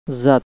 zat like z in "zone", often replaced with, and pronounced like s
speaker.gif (931 bytes) Click on the word to hear it pronounced.